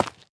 minecraft / sounds / block / tuff / break2.ogg
break2.ogg